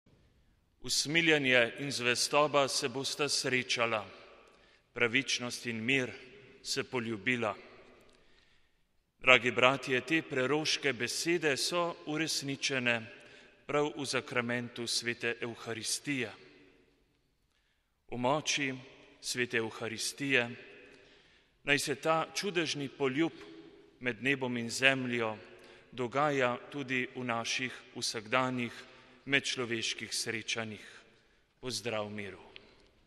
Pridiga (začetek in konec v italijanščini ostalo v slovenščini)